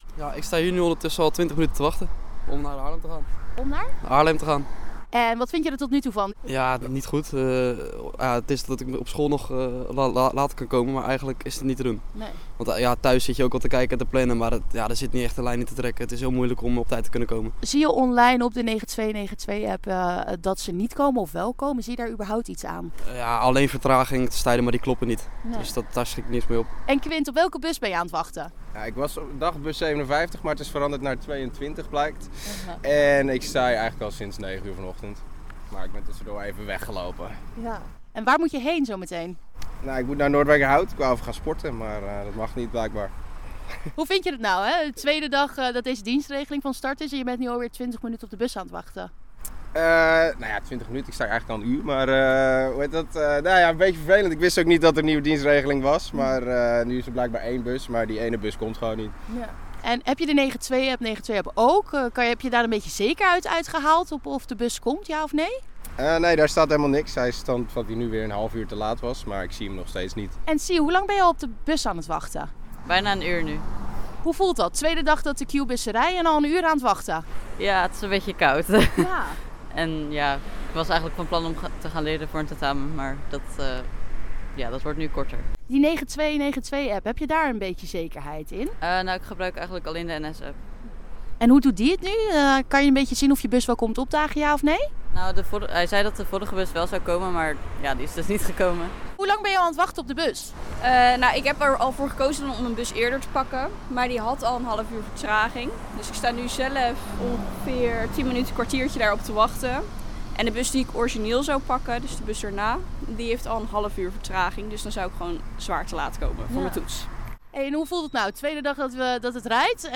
sprak met enkele reizigers uit de omgeving over hun ervaring.